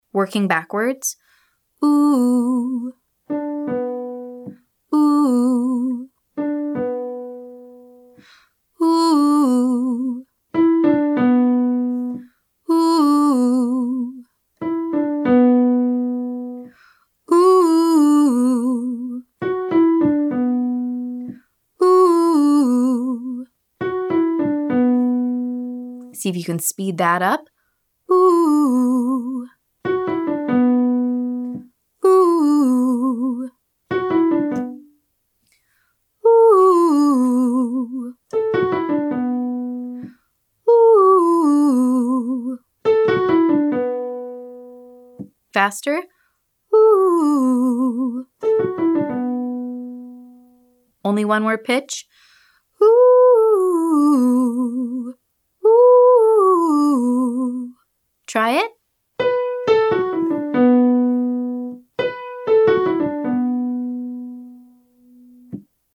Improving Speed - Online Singing Lesson